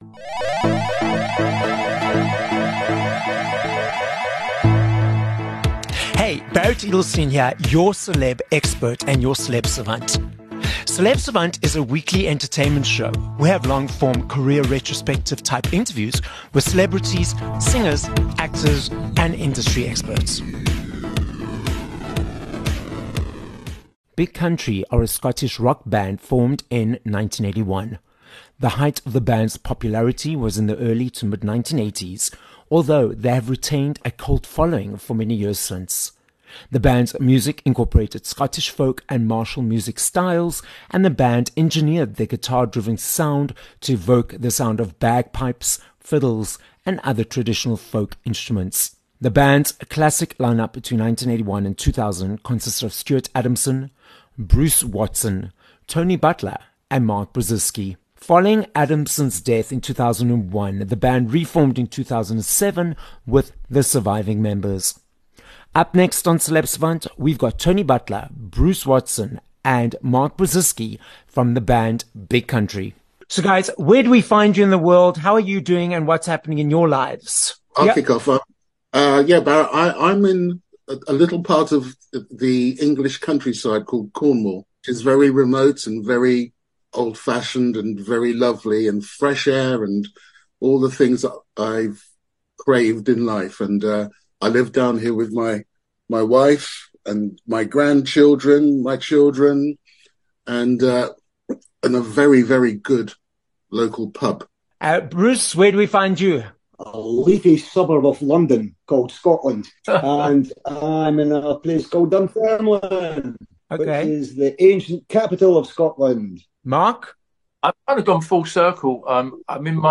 22 May Interview with Big Country